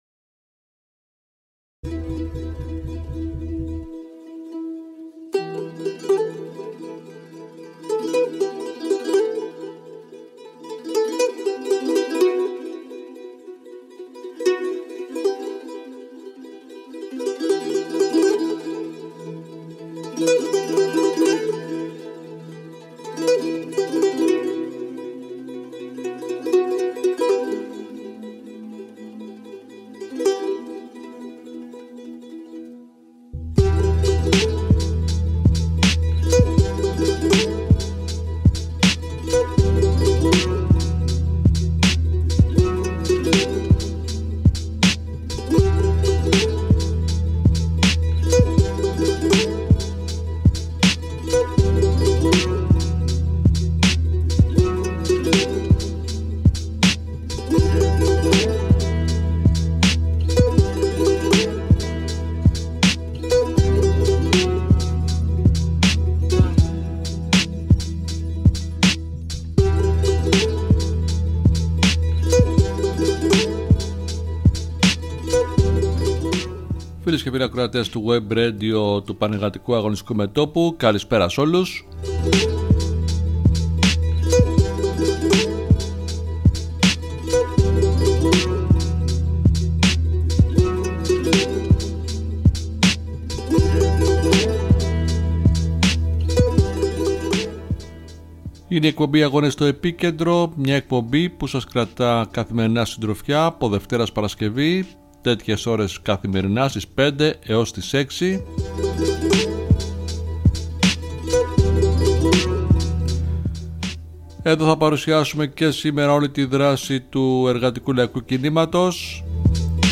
Καθημερινό ενημερωτικό μαγκαζίνο με θέματα την επικαιρότητα και τη δράση των ταξικών δυνάμεων απ’ όλη την Ελλάδα.